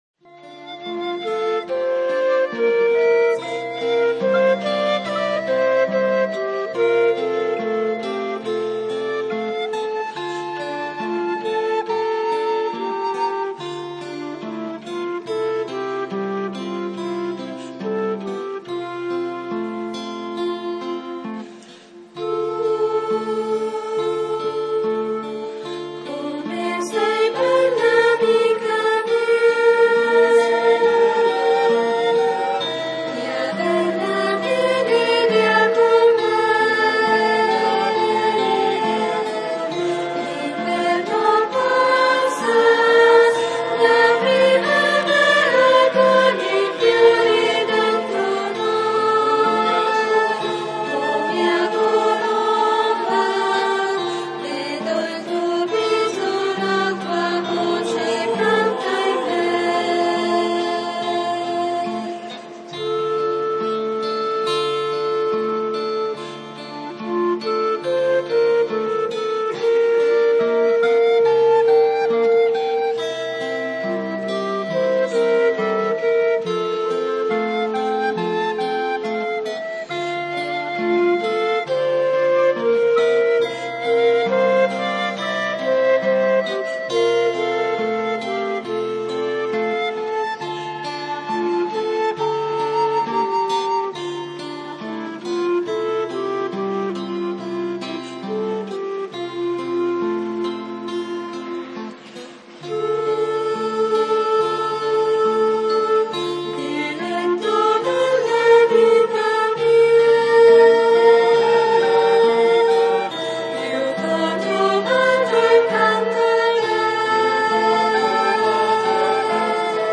V domenica di Pasqua (Pasqua e matrimonio)
canti: